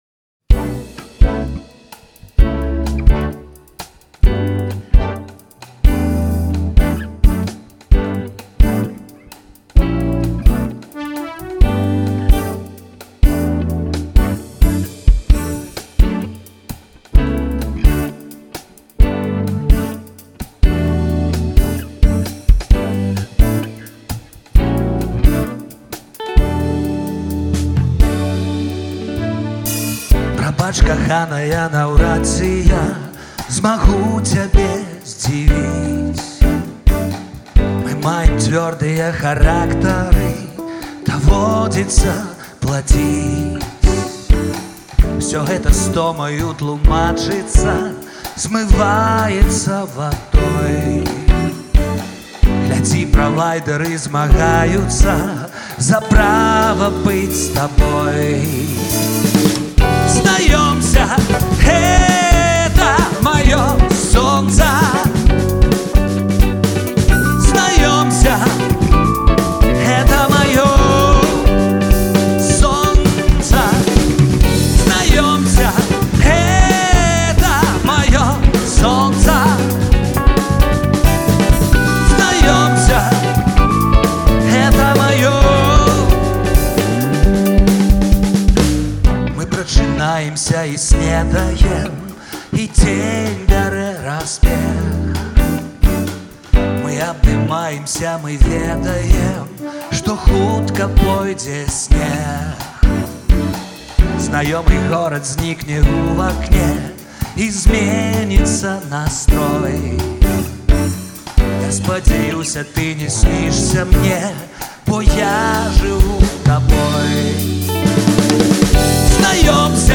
Цяпер у джазавай вэрсіі.